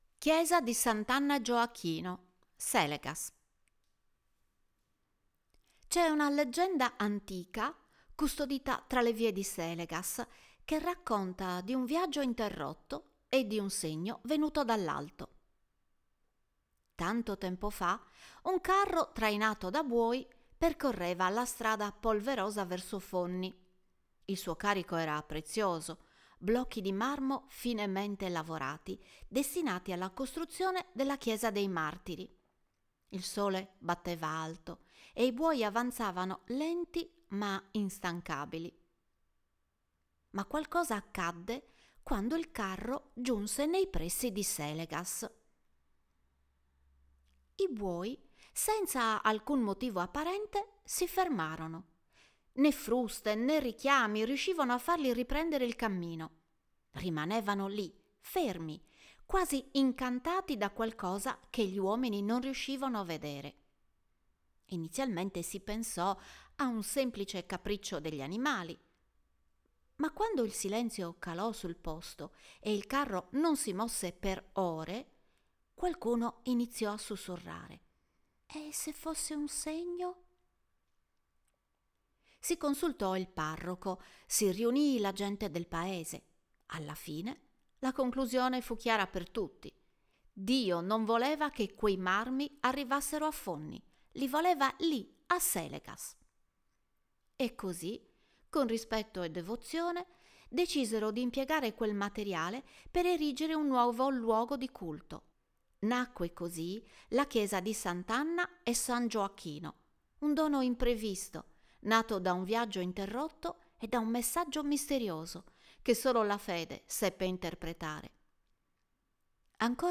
Voce Narrante